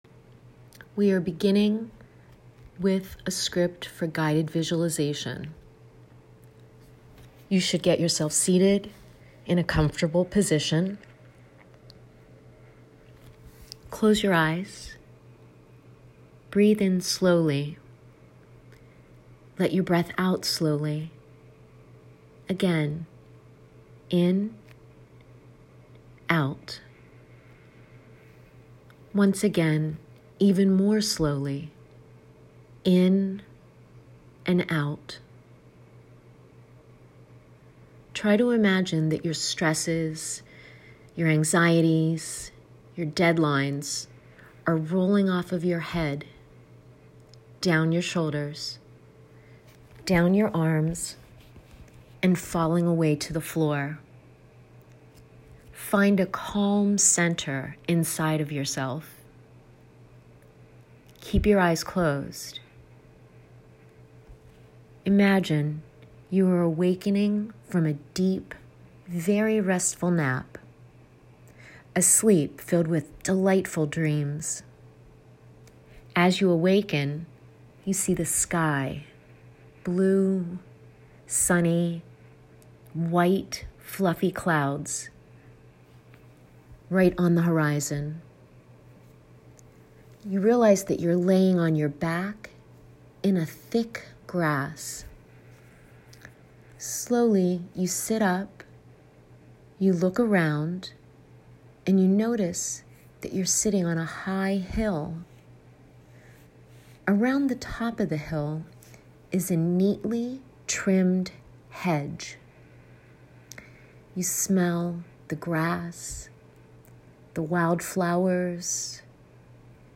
Listen to the Vision Board Guided Meditation.